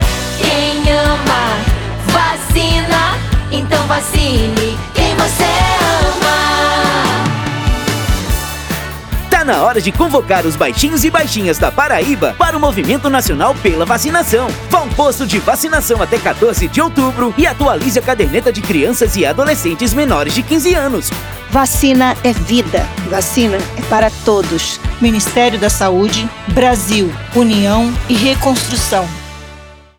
Áudio - Spot 30seg - Campanha de Multivacinação na Paraíba - 1,1mb .mp3